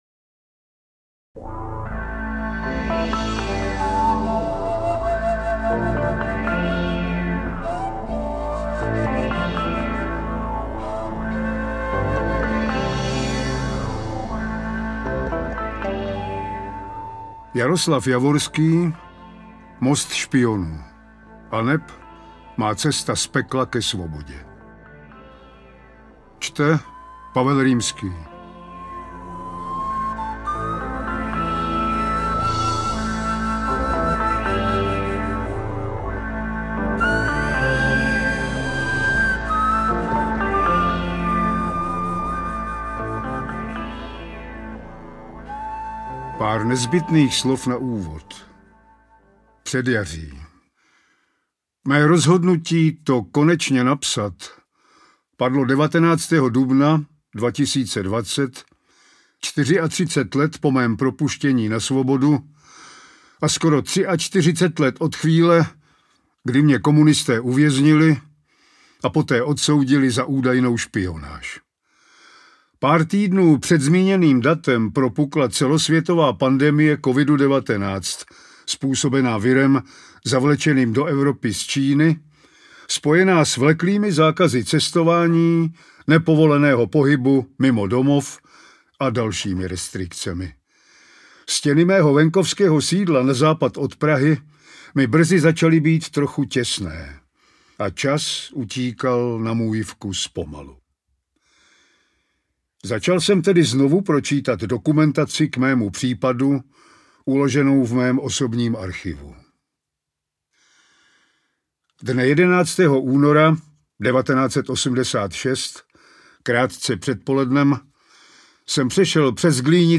• AudioKniha ke stažení Javorský: Most špionů aneb má cesta z pekla na svobodu
Interpret:  Pavel Rimský